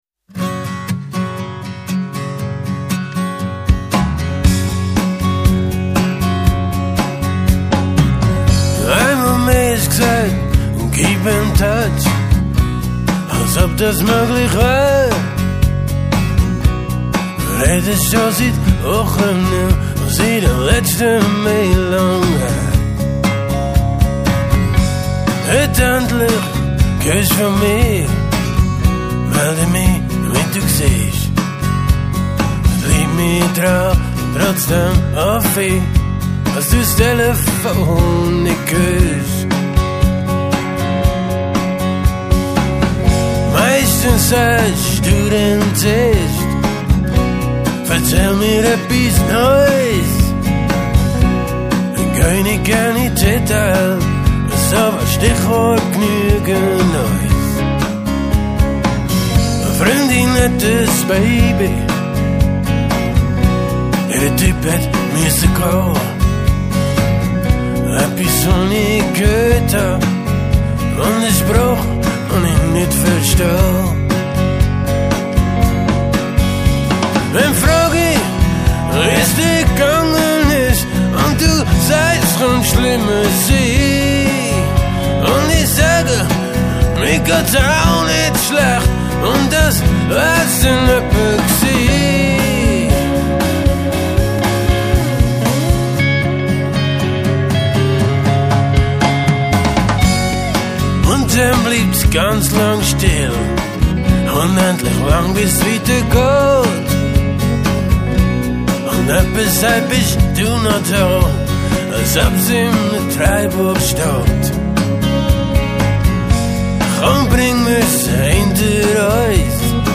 Unspektakulär, treffend und mit schönen Gitarren
guitars
drums
bass
vocals